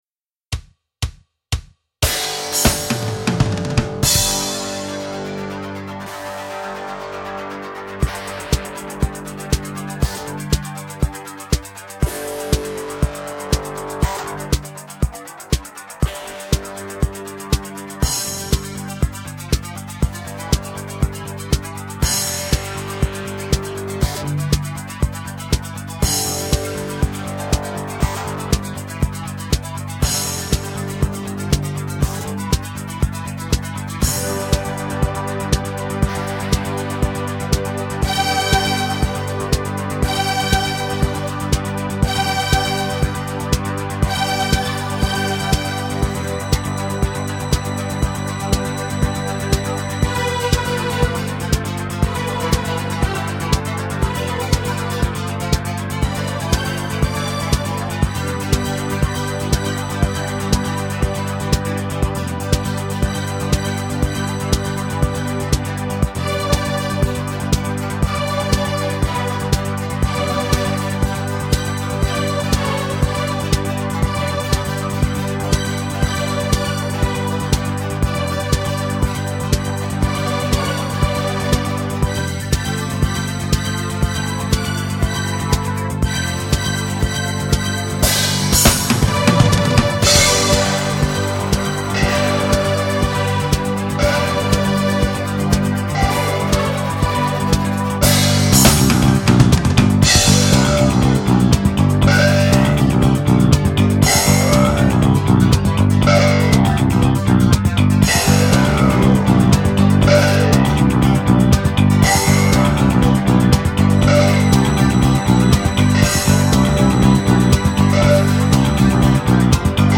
Попробовал на компе музыку написать